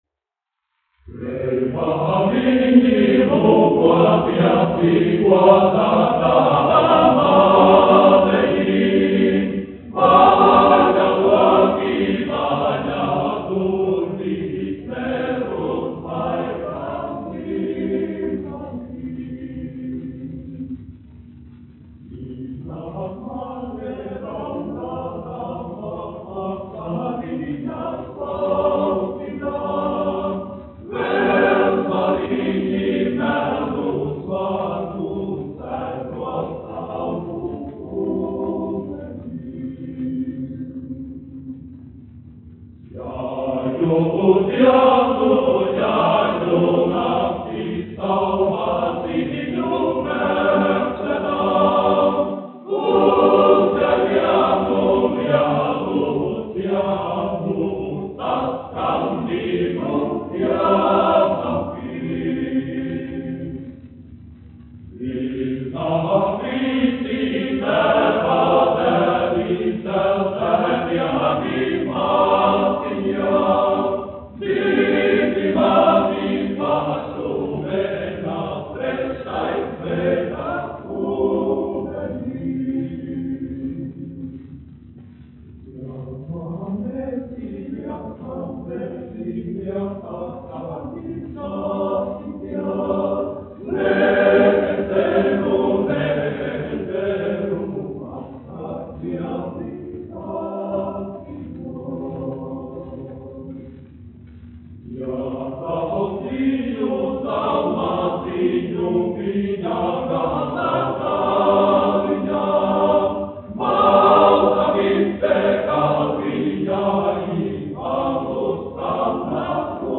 1 skpl. : analogs, 78 apgr/min, mono ; 25 cm
Kori (vīru)
Latviešu tautasdziesmas
Skaņuplate